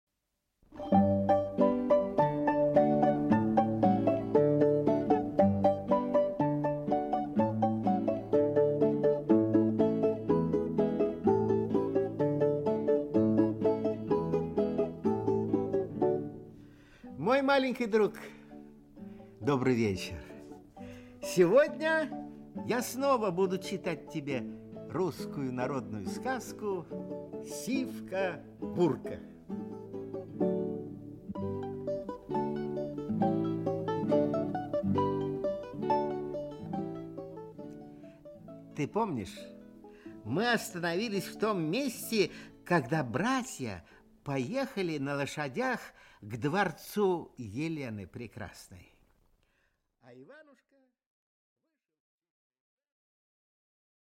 Аудиокнига Сивка-Бурка. Часть 2 | Библиотека аудиокниг
Часть 2 Автор Народное творчество Читает аудиокнигу Николай Литвинов.